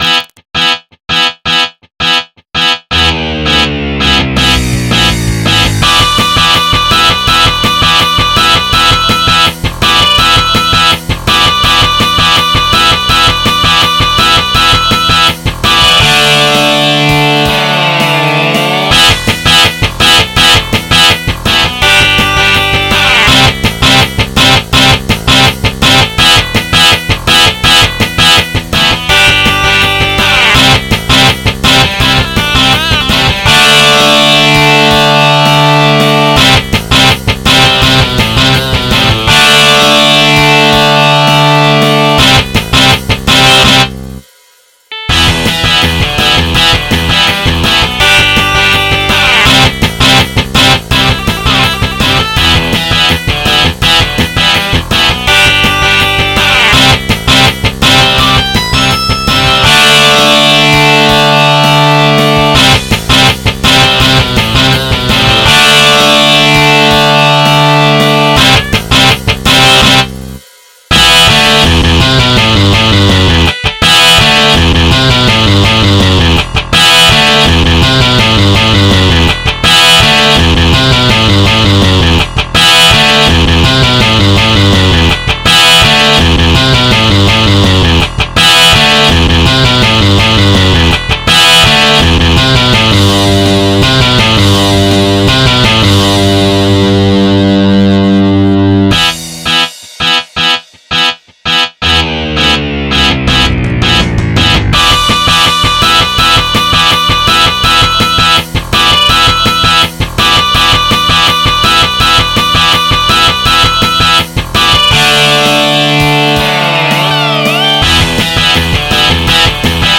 MIDI 55.01 KB MP3 (Converted) 3.53 MB MIDI-XML Sheet Music